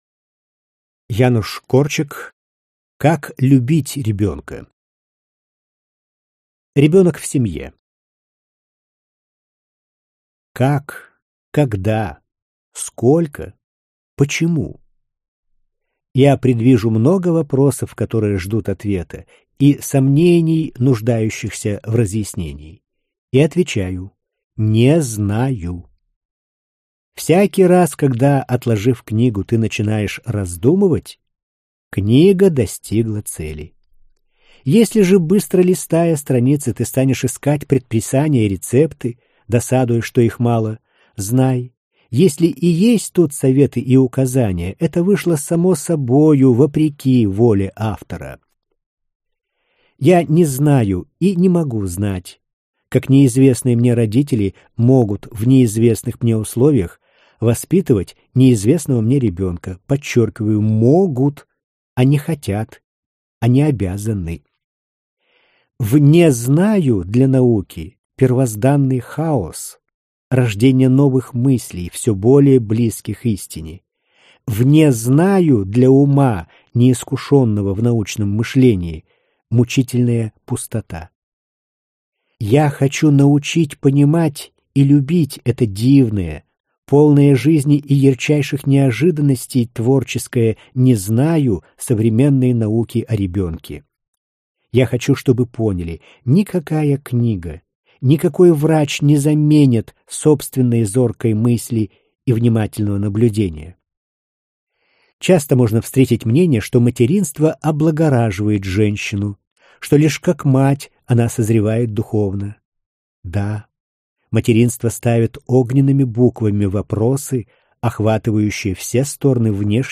Аудиокнига Как любить ребенка - купить, скачать и слушать онлайн | КнигоПоиск